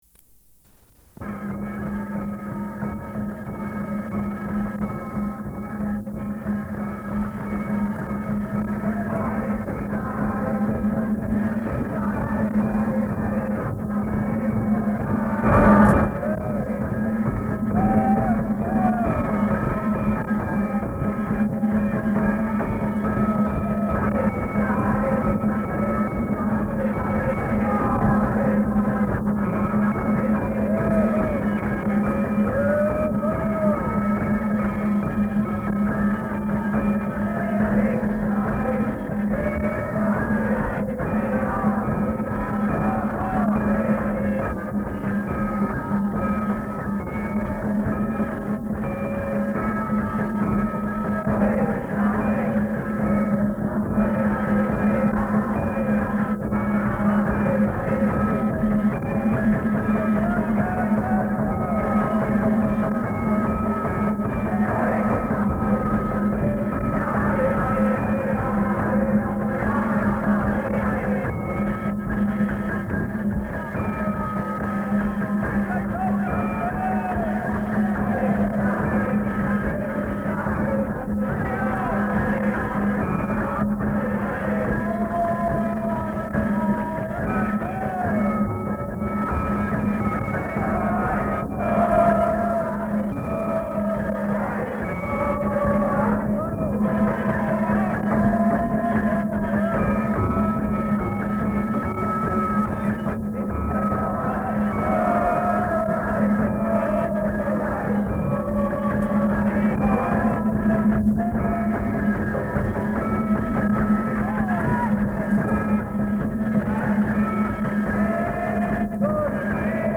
Śrīmad-Bhāgavatam 1.2.5 --:-- --:-- Type: Srimad-Bhagavatam Dated: April 3rd 1972 Location: Sydney Audio file: 720403SB.SYD.mp3 [ kīrtana ] [02:57] Prabhupāda: [ prema-dhvani ] Thank you very much.